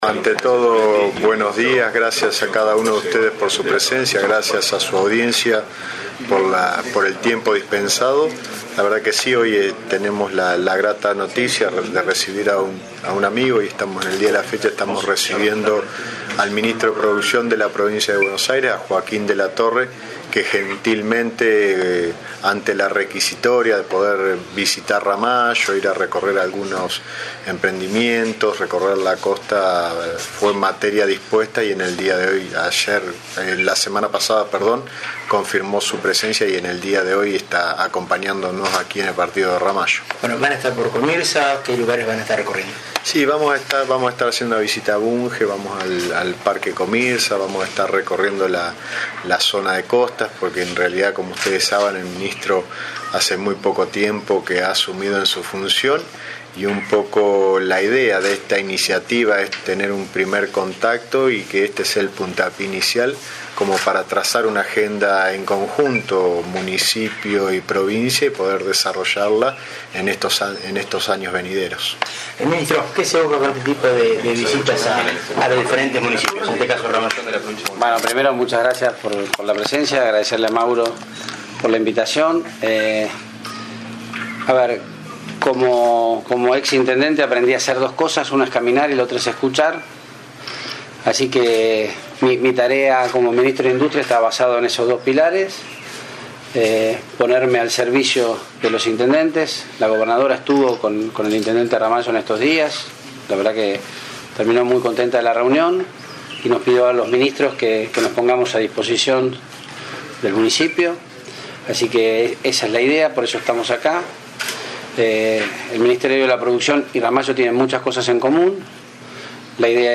Con muy pocas novedades y un panorama muy conocido se prestó a una extensa charla retrospectiva con todo lo sucedido al desabastecimiento de la energía y no quiso adelantar nada nuevo. Solo enfatizó lo lento que va a ser mover el aparato productivo del país y recomponerlo.